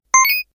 powerUp9.ogg